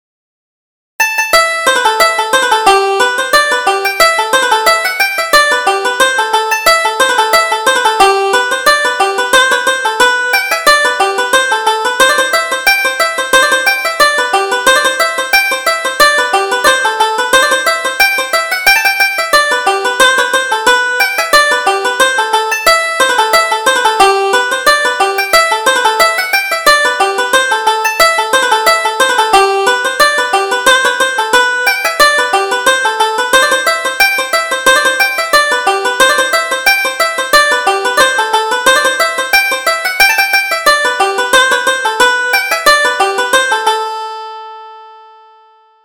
Reel: The Curragh Races